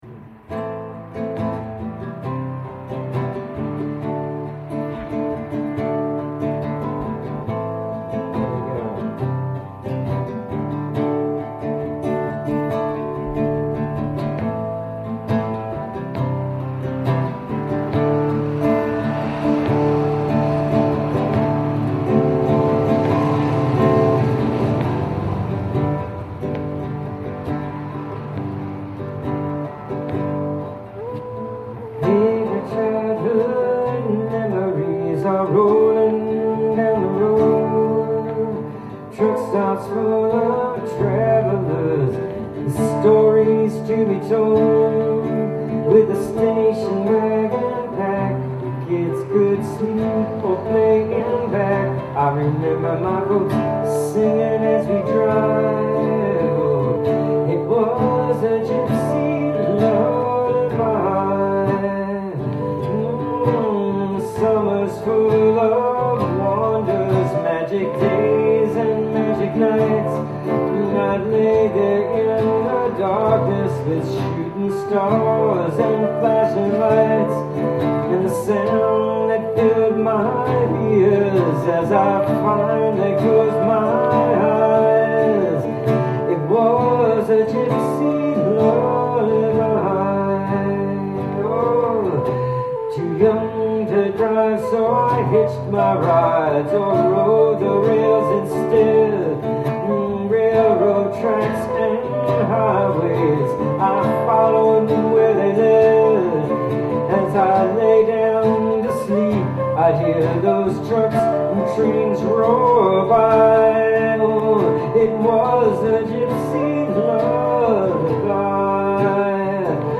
Solo Vocals and Guitar, ceramic flute